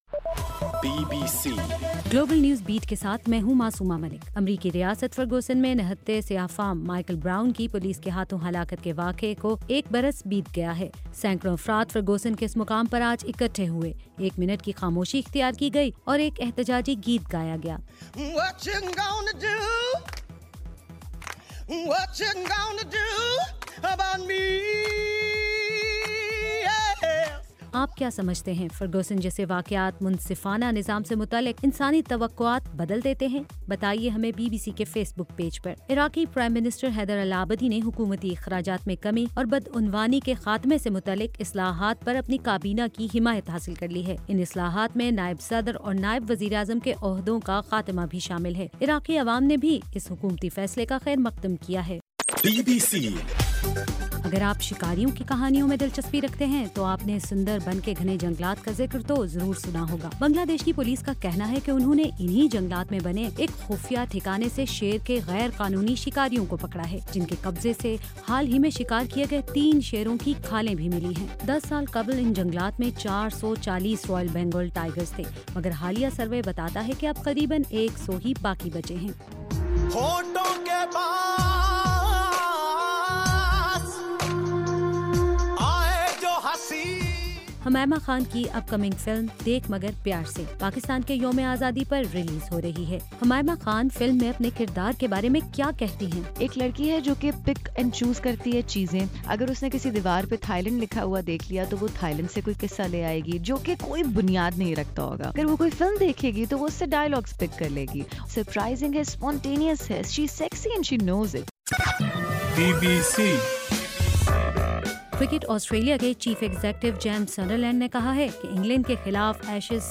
اگست 11: رات 1 بجے کا گلوبل نیوز بیٹ بُلیٹن